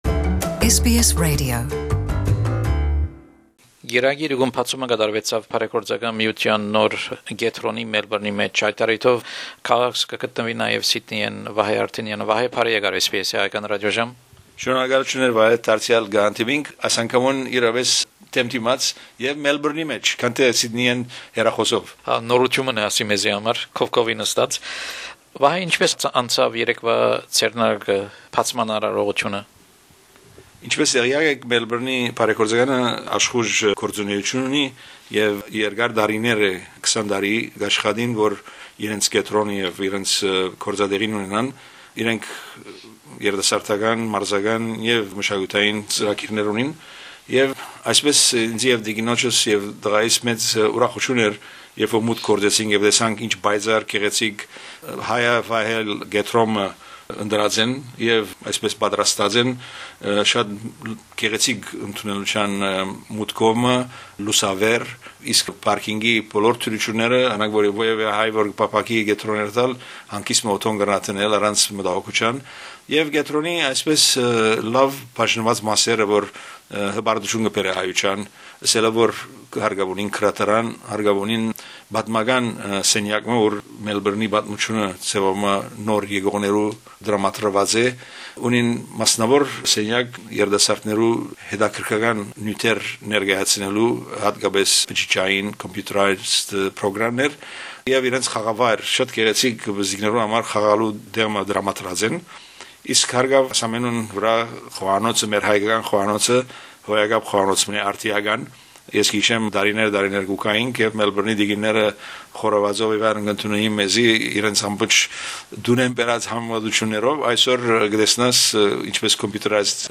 Հարցազրոյց